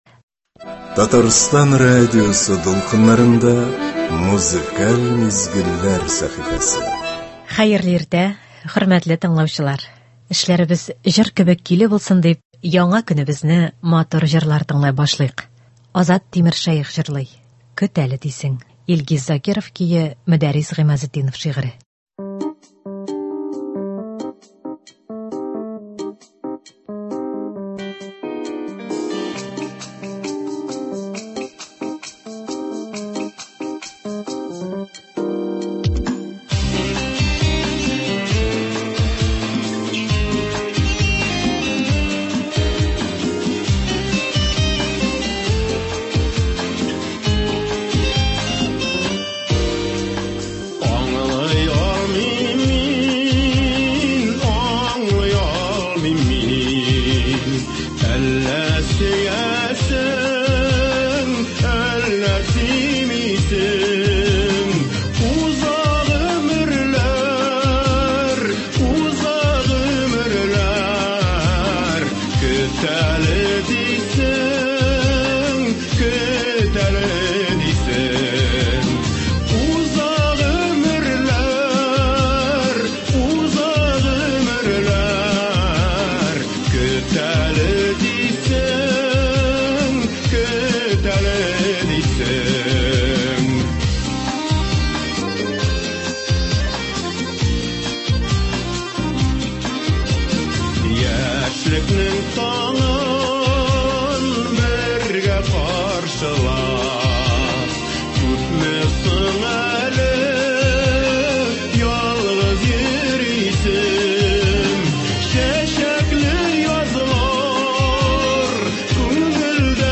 Без сезнең өчен, хөрмәтле радиотыңлаучыларыбыз, яхшы кәеф, күңел күтәренкелеге бирә торган концертларыбызны дәвам итәбез.